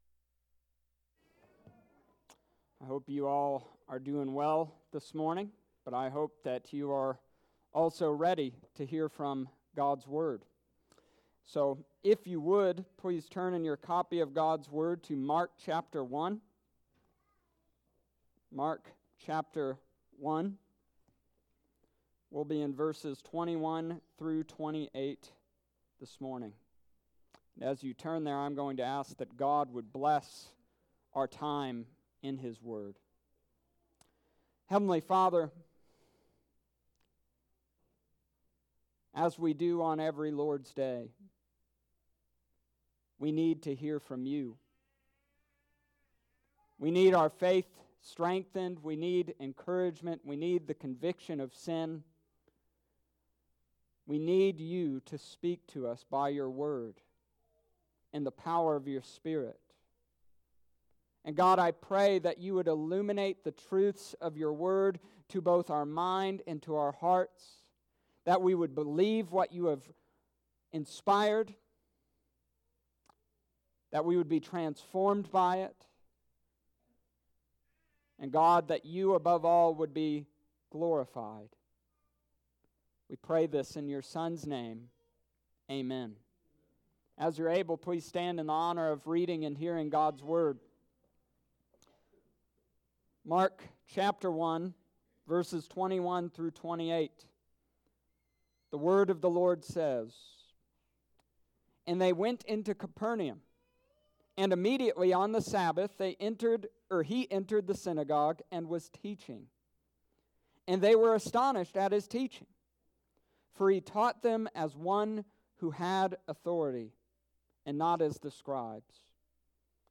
The Amazing Authority of Jesus Mark Chapter 1 verses 21-28 Sunday morning Feb. 9th 2020
The-Amazing-Authority-of-Jesus-Feb-9th2020-Sunday-morning-serrvice.mp3